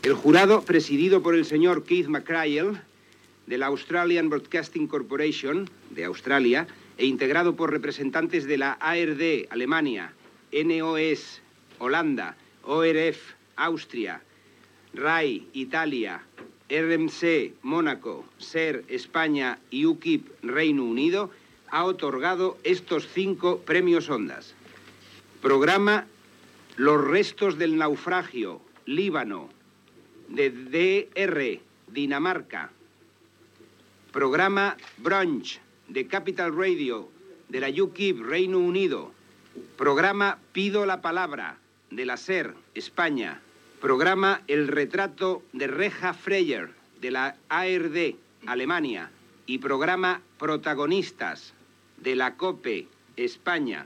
Fragment de la lectura del veredicte del jurat dels premis Ondas 1986.
Informatiu